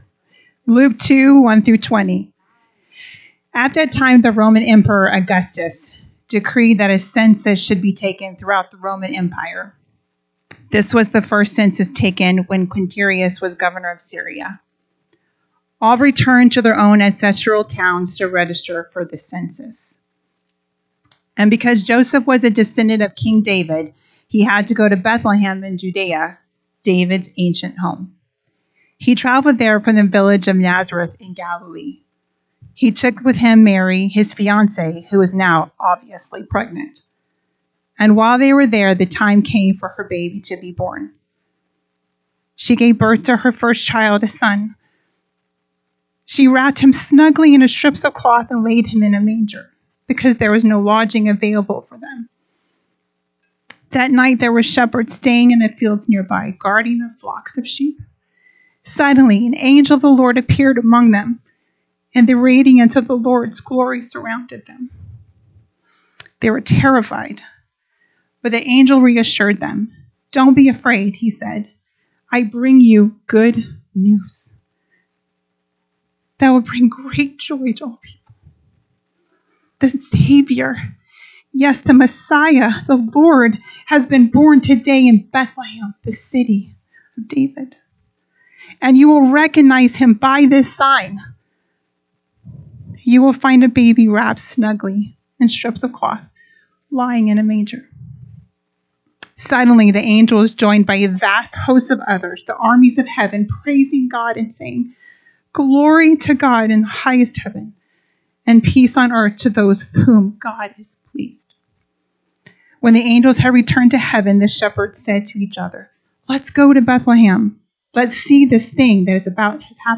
NSCF Sermons Online Not the "Inn" Crowd - Shepards and Angels Dec 02 2024 | 00:41:27 1x 00:00 / 00:41:27 Subscribe Share RSS Feed Share Link Embed